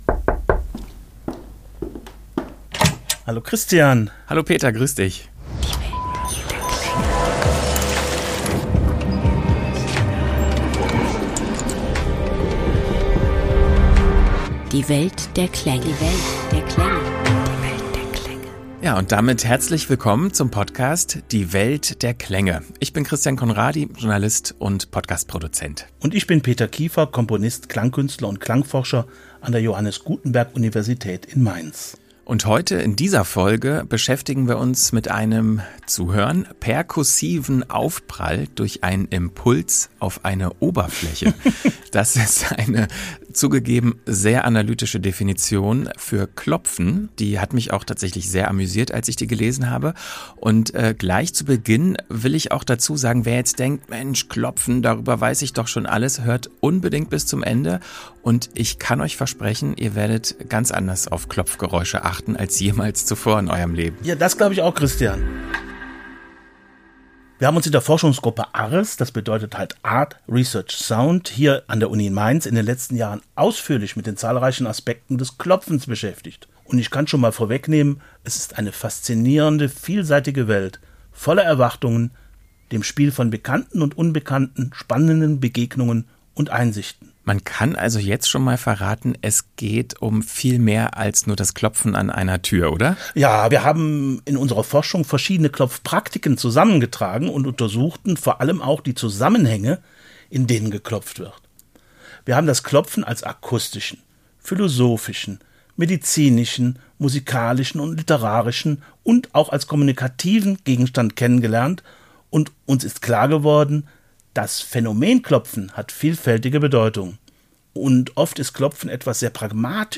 Außerdem hören wir in die Kapuzinergruft in Wien, wo bei Bestattungsritualen an die Kirchentüre geklopft wird.
Und natürlich darf die Musik nicht fehlen, in der klopfende Instrumenten Kompositionen neue Klänge entlocken.